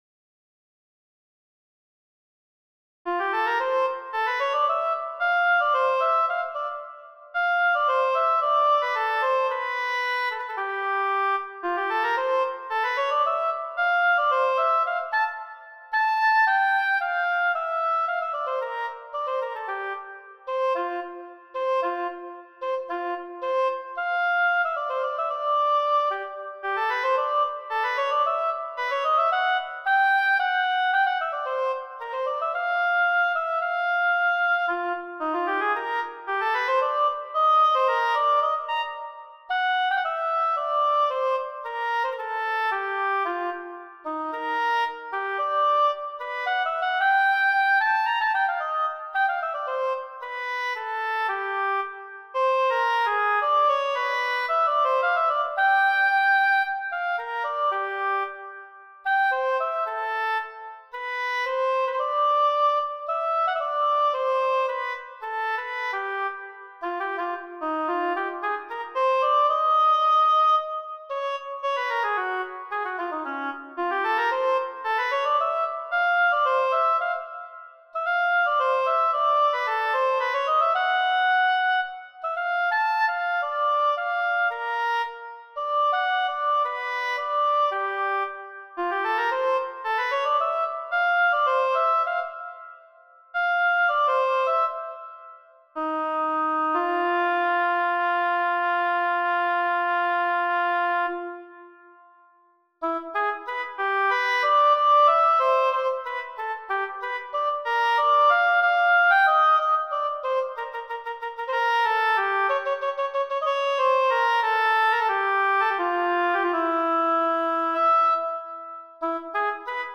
1) Allegro ma non troppo, 2) allegretto ma non troppo, 3) largo, 4) allegro and a final 5) andante.
Tidbits_for_Solo_Oboe.mp3